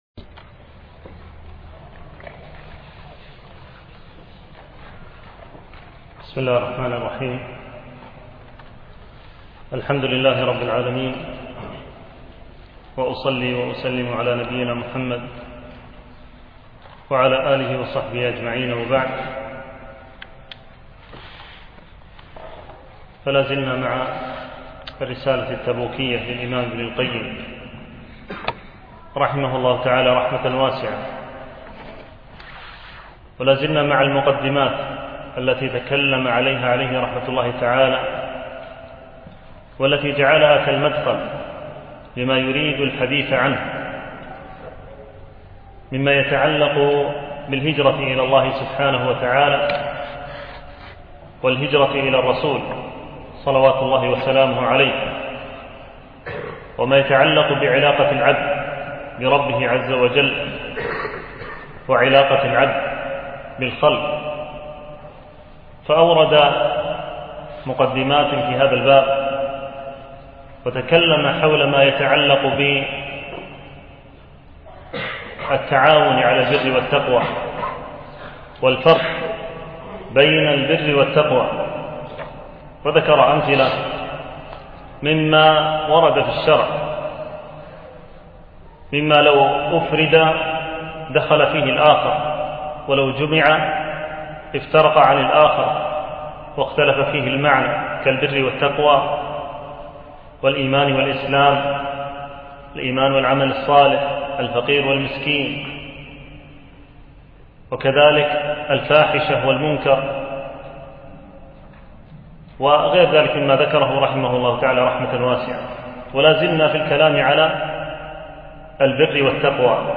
شرح الرسالة التبوكية - الدرس الثاني